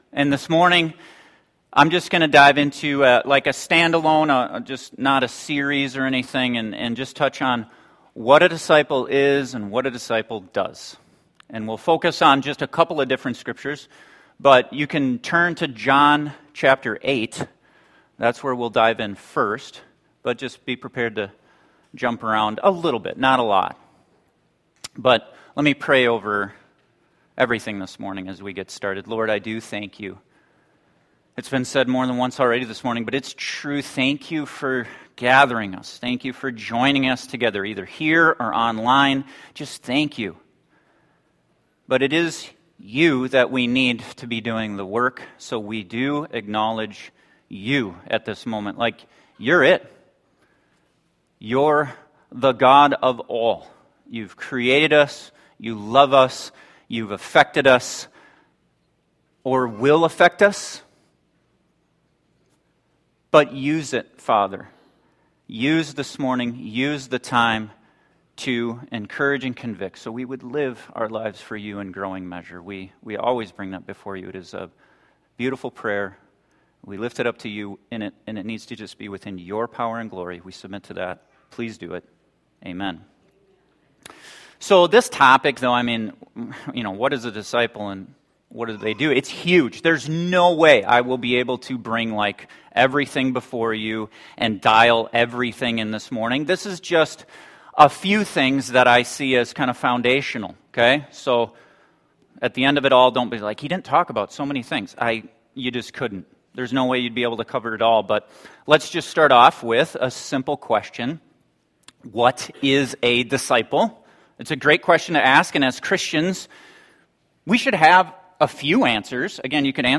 This sermon focuses on the characteristics every disciple of Christ should be pursuing in their lives.